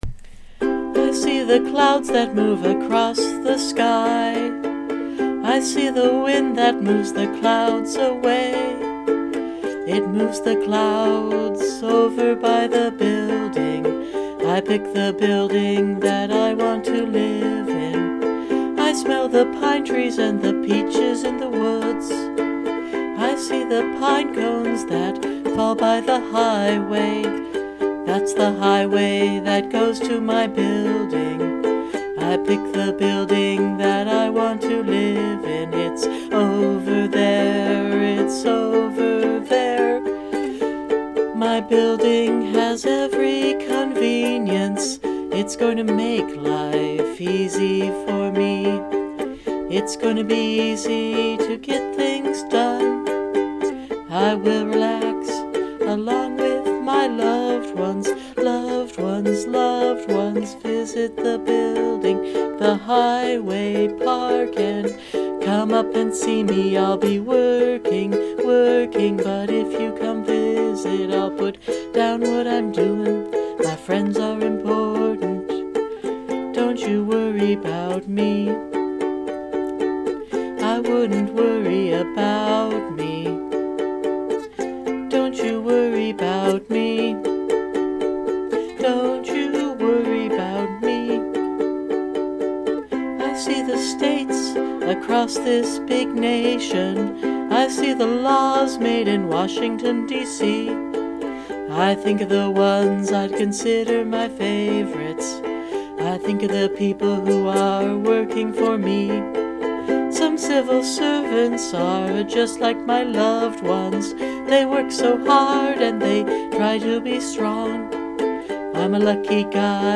Sort of a mellow mood today.